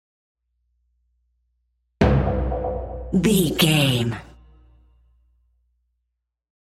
Dramatic Hit Trailer
Sound Effects
Fast paced
In-crescendo
Atonal
heavy
intense
dark
aggressive
hits